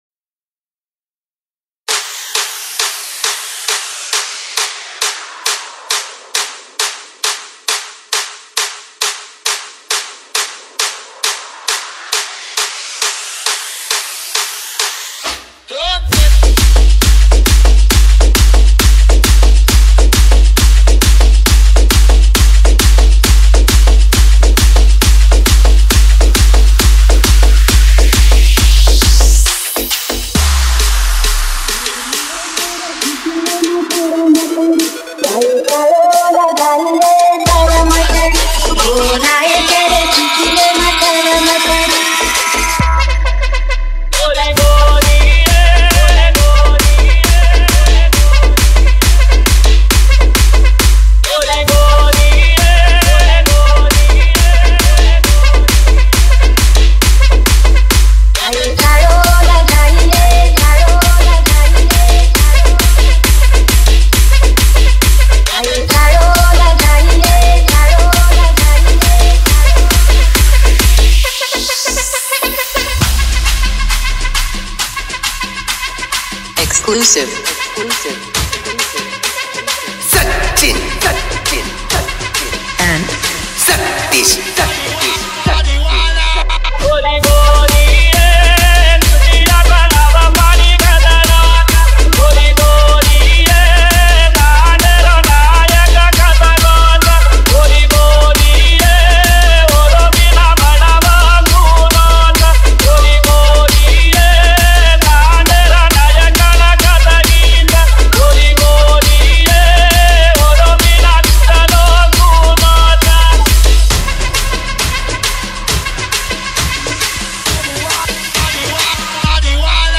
BANJARA DJ SONG 0